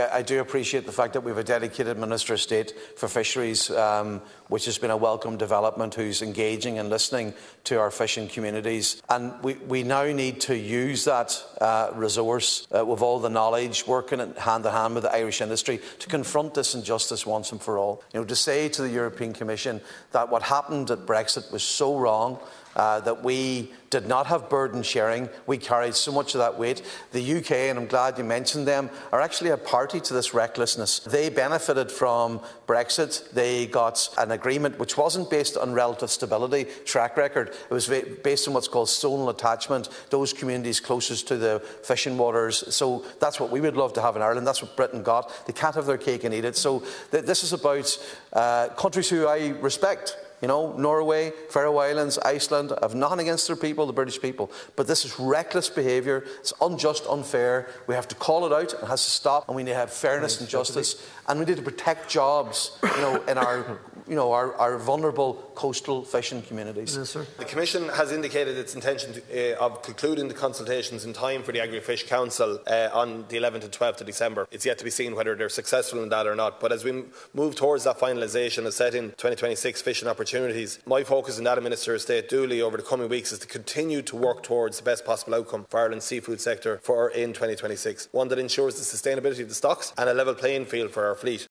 During questions to the Minister for Agriculture, Food and the Marine, Deputy MacLochlainn said that Ireland must confront this injustice.
Minister Martin Heydon, says he is continuing to try and get the best outcome for Ireland’s fishing sector in 2026: